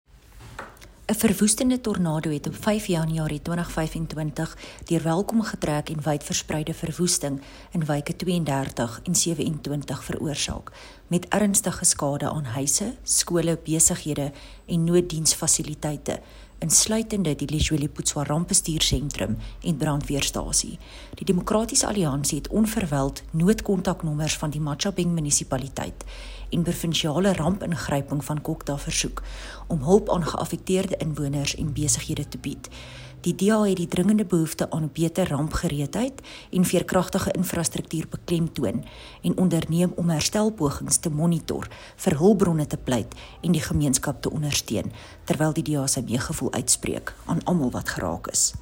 Afrikaans Soundbites by Cllr René Steyn with pictures here, here, here, and here